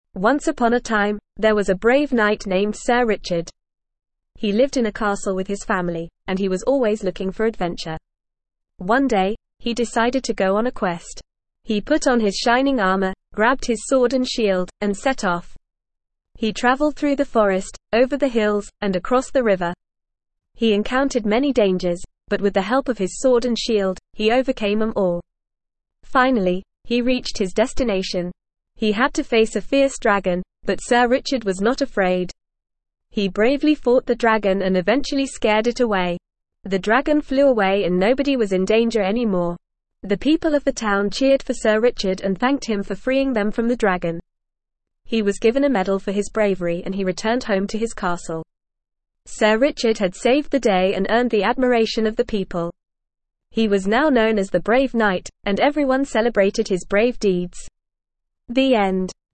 Normal
ESL-Short-Stories-for-Kids-NORMAL-reading-The-Brave-Knight.mp3